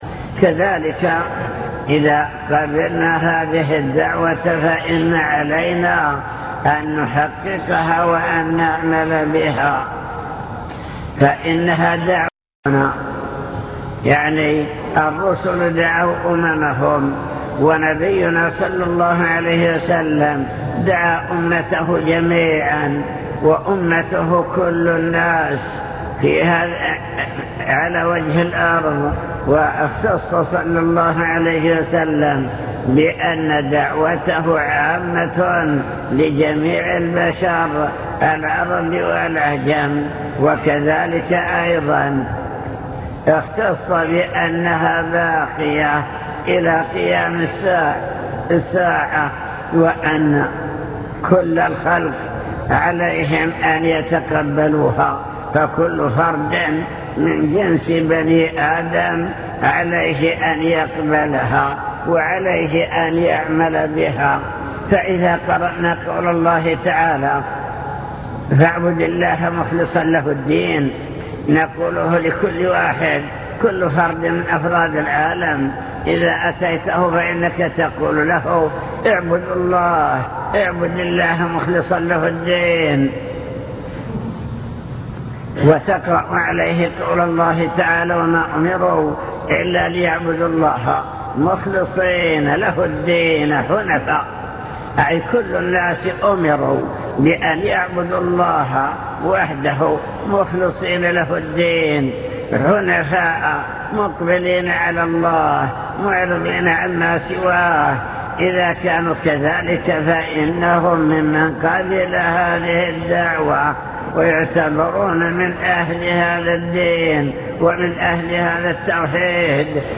المكتبة الصوتية  تسجيلات - محاضرات ودروس  محاضرة في جامع حطين دعوة الأنبياء والرسل